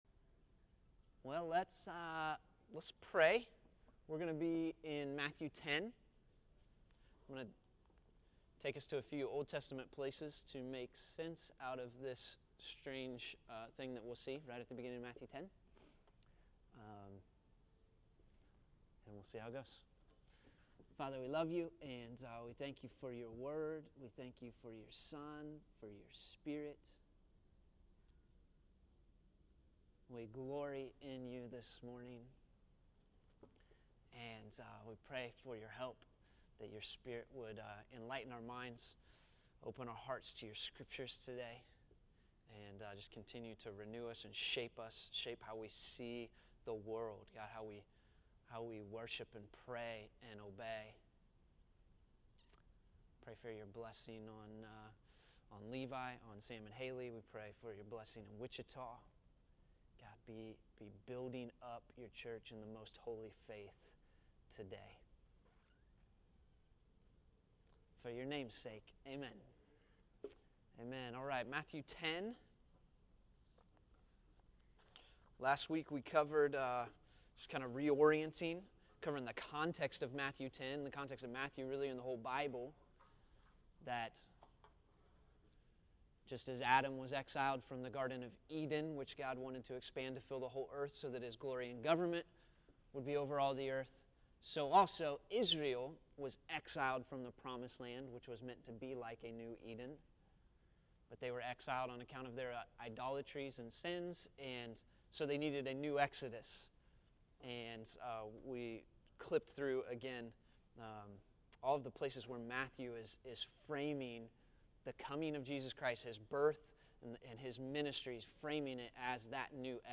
Matthew 10:6 June 16, 2013 Category: Sunday School | Location: El Dorado Back to the Resource Library Jesus' commission to the Twelve to go to the lost sheep of Israel was a fulfillment of New Exodus prophecies. But we still must ask why He forbid them to go the Samaritans and Gentiles at this stage.